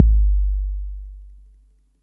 TR 808 Kick 01.wav